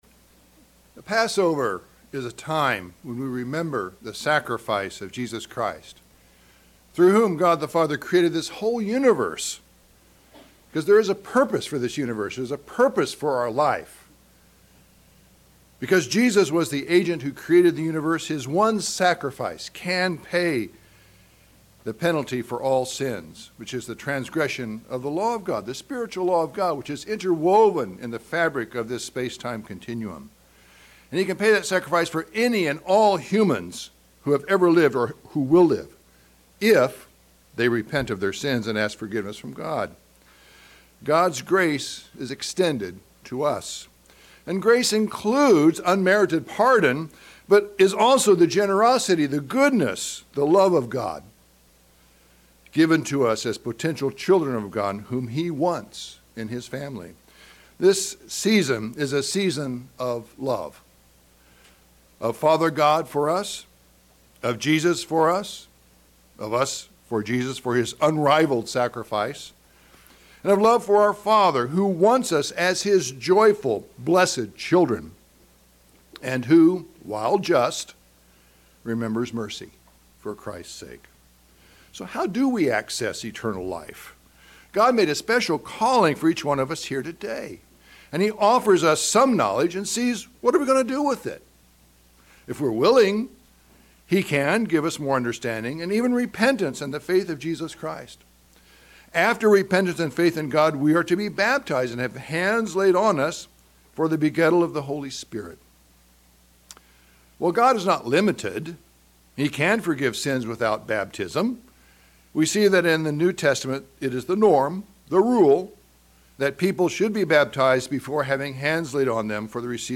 Sermons
Given in Northern Virginia